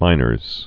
(mīnərz)